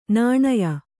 ♪ nāṇaya